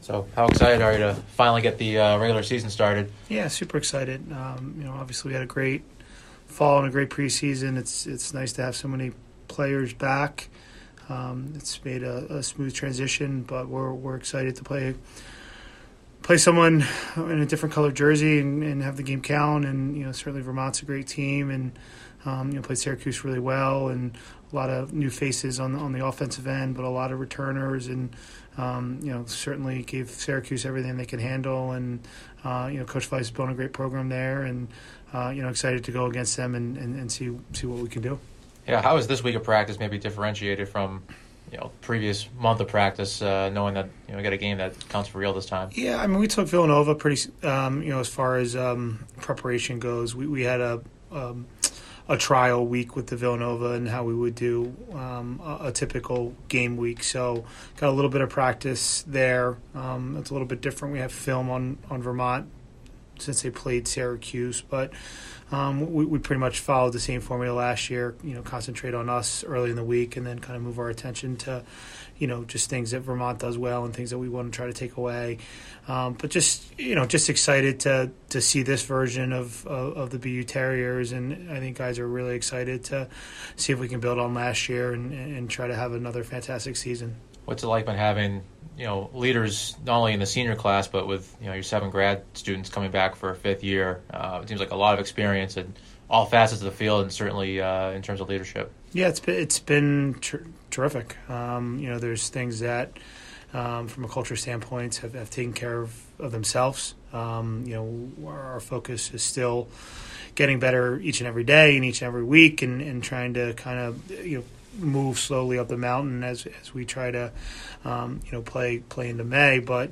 Men's Lacrosse / Vermont Pregame Interview (2-10-23)